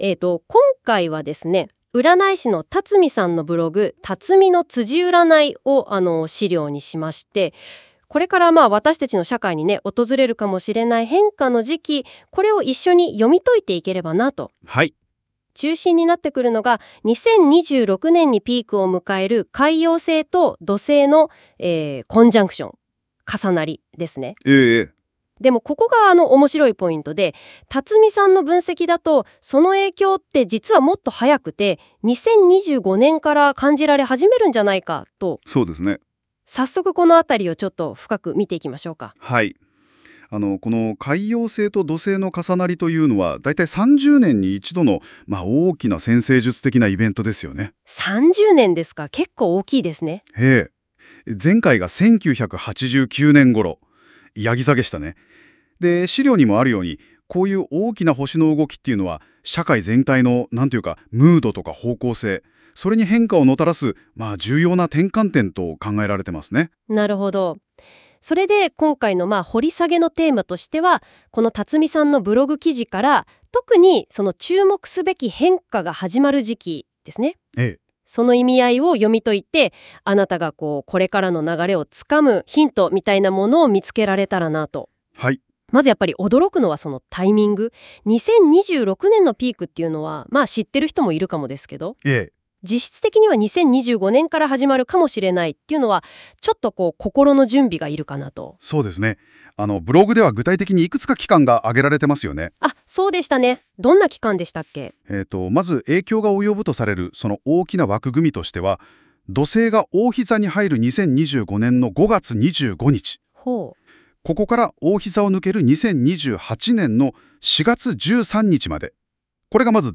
今回、実験的にnotebookLMで音声概要をしてみました。